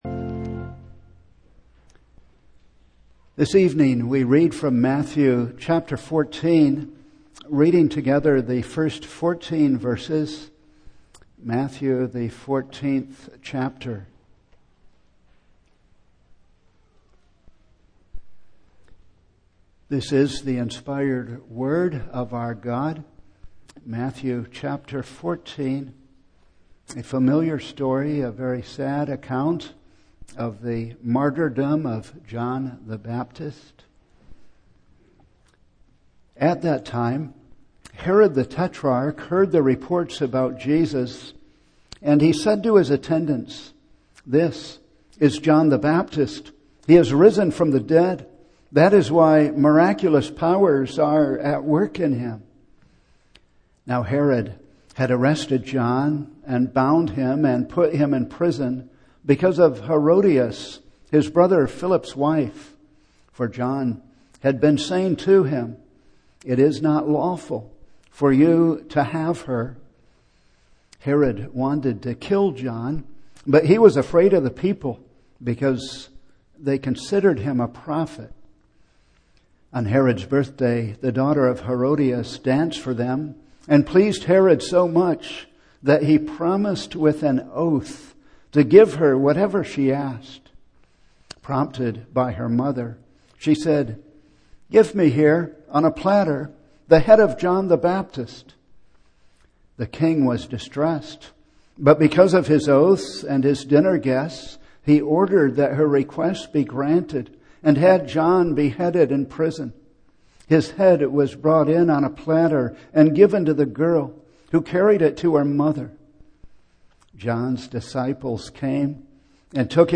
Single Sermons
Service Type: Evening